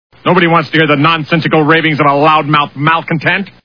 The Simpsons [Celebrities] Cartoon TV Show Sound Bites